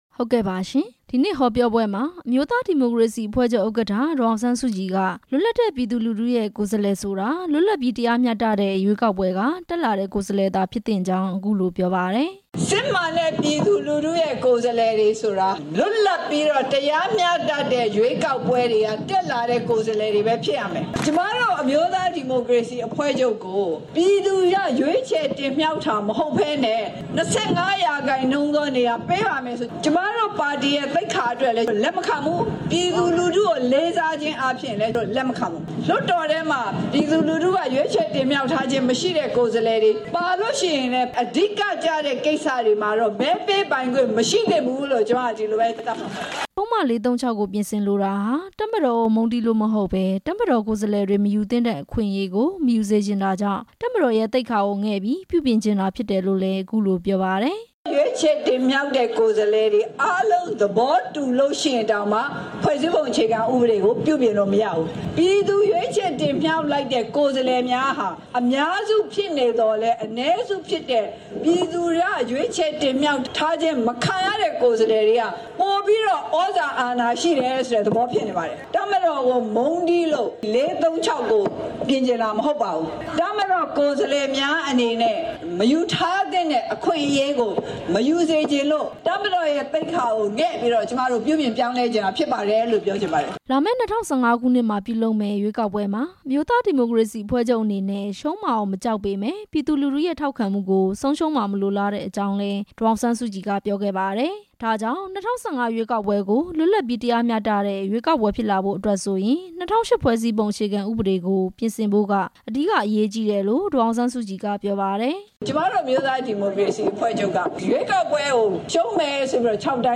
ဒီနေ့ တောင်ငူမြို့ အားကစားကွင်းမှာကျင်းပတဲ့ ဖွဲ့စည်းပုံ အခြေခံဥပဒေ ပြင်ဆင်ရေး လူထုဟောပြောပွဲမှာ ဒေါ်အောင်ဆန်းစုကြည်က အခုလို ပြောပါတယ်။